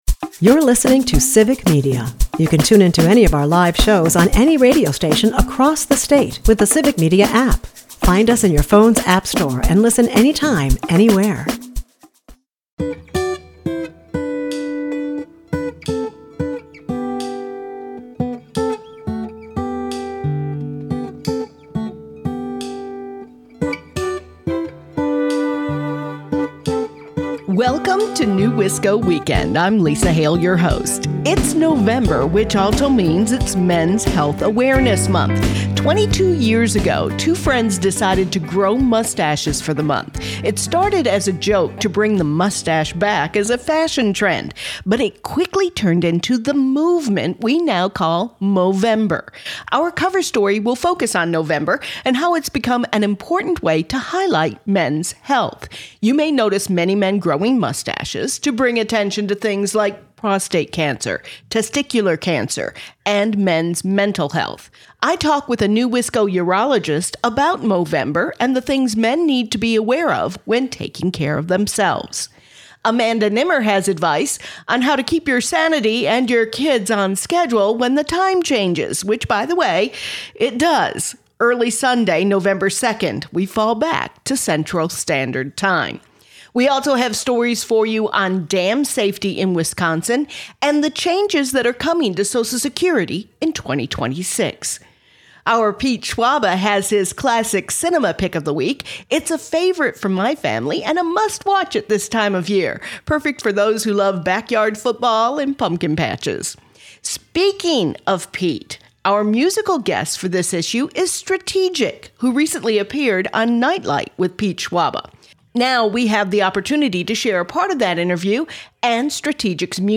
P.E. NEWisco Weekend is a part of the Civic Media radio network and airs Saturdays at 8 am and Sundays at 11 am 98.3 and 96.5 WISS.